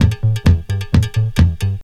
DISCO LOO03R.wav